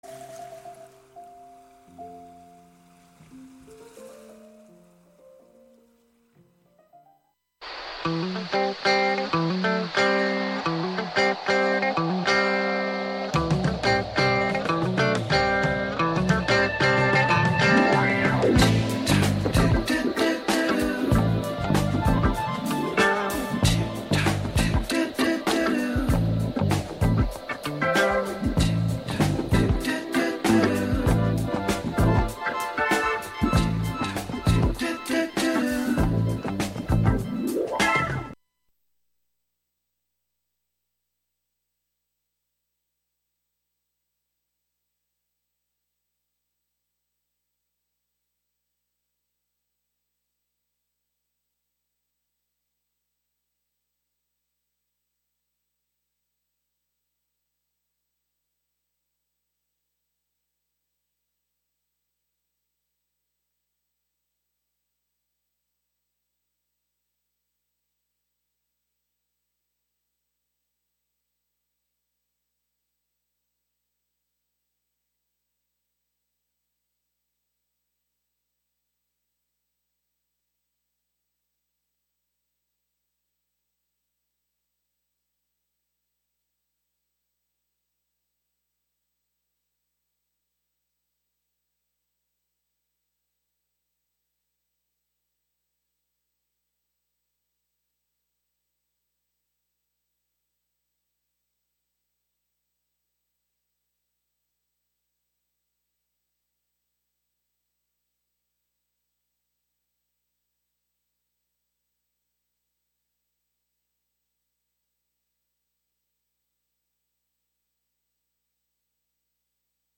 In-studio / pedestrian interviews, local event listings, call-ins, live music, and other chance connections will be sought on air.